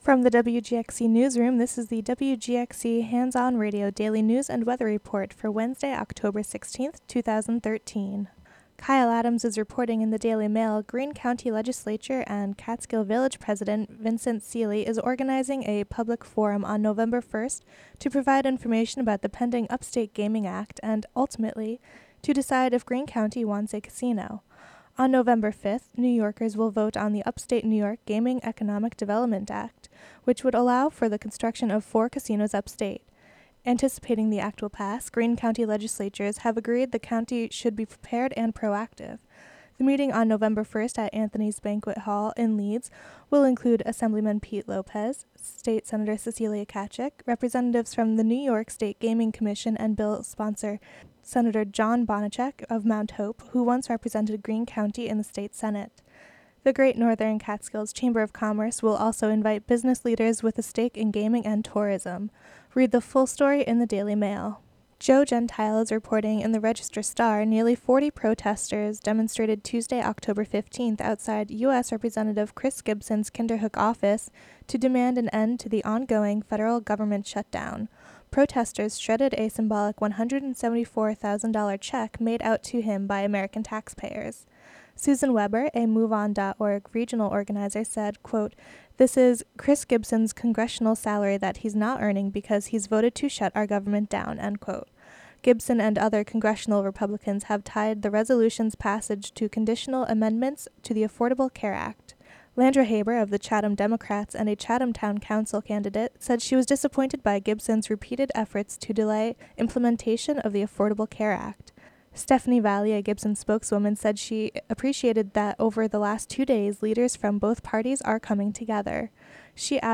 Local news and weather for Wednesday, October 16, 2013.